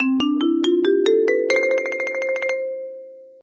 marimba_scale_up.ogg